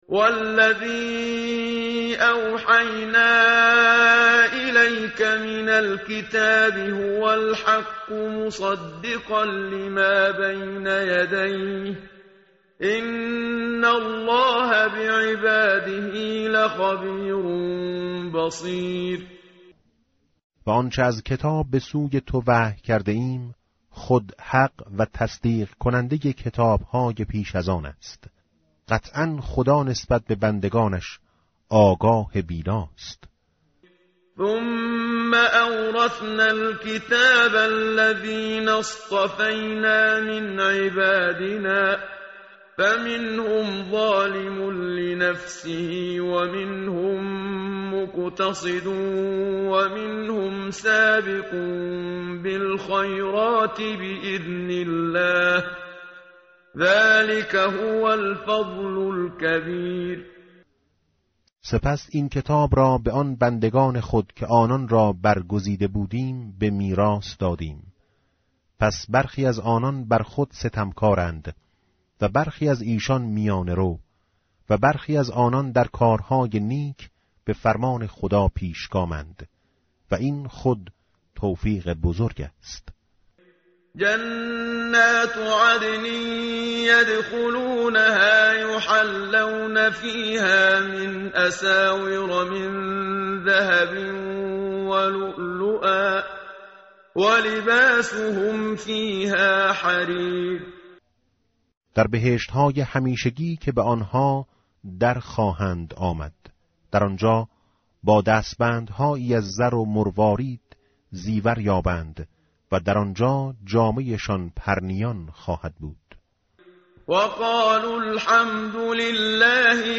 tartil_menshavi va tarjome_Page_438.mp3